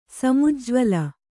♪ samujjvala